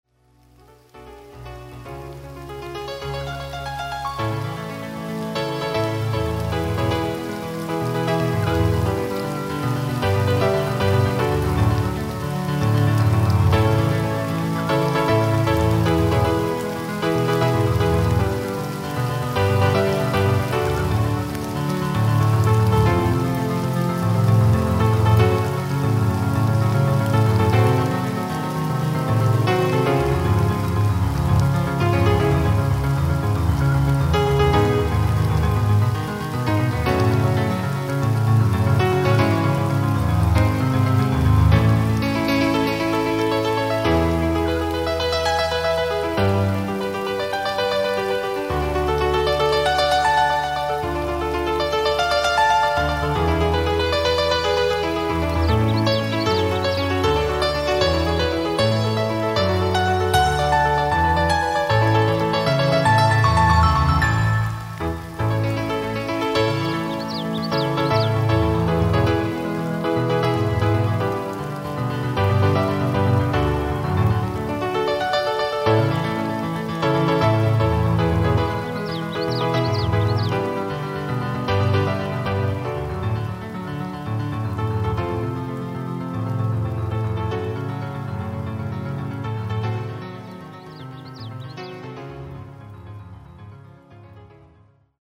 szintetizátoros betéteket írtam